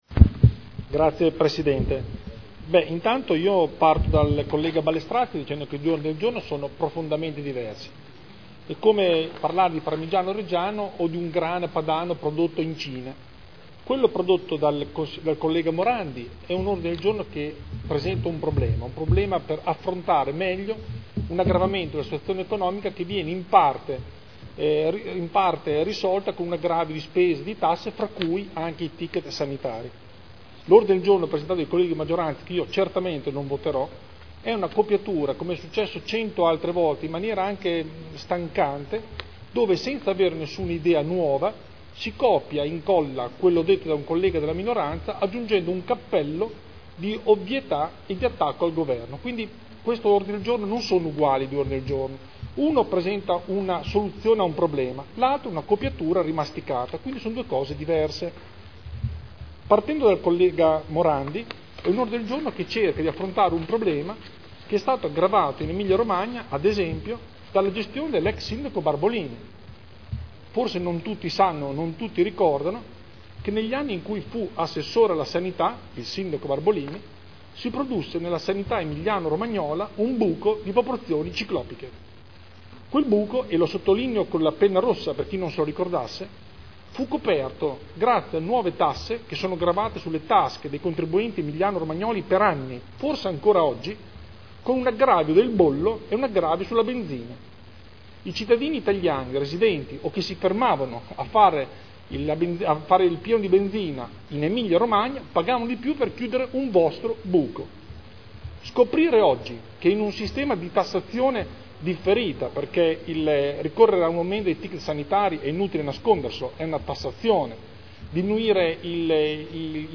Seduta del 12/09/2011. Dibattito su Ordini del Giorno relativi all'introduzione del ticket sanitario.